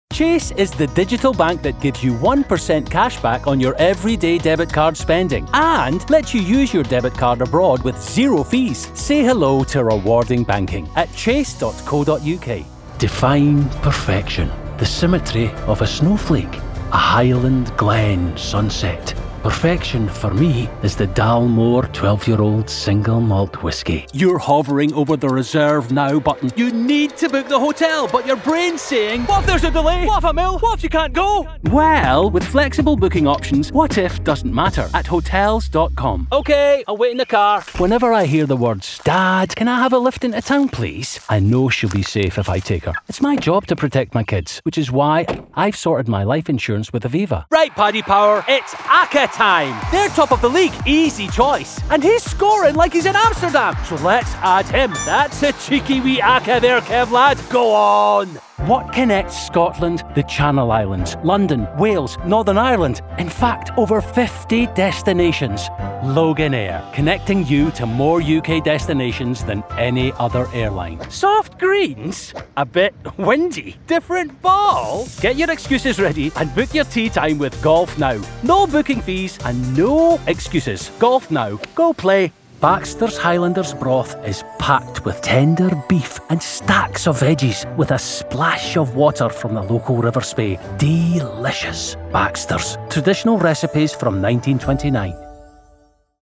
United Nations Voiceover Commercial Talent Online
a versatile British male voiceover with a natural, strong, authoritative, masculine and mature voice